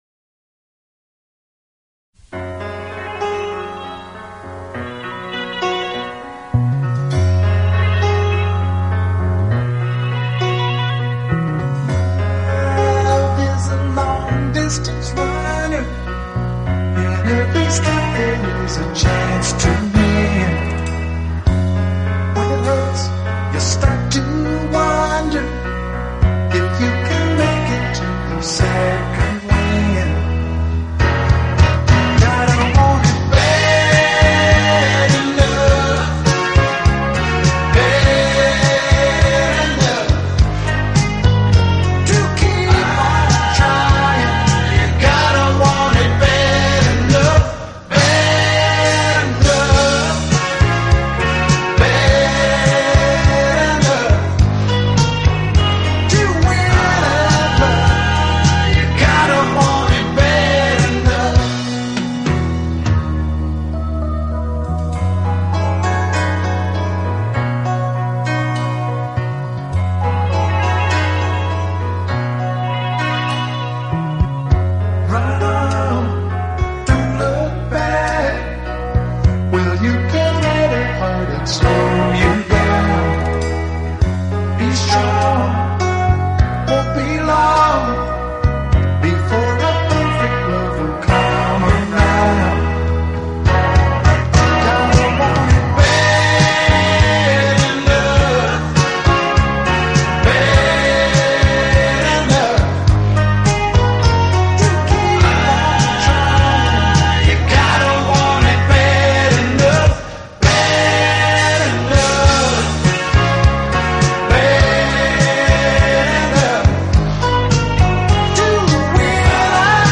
【乡村歌曲】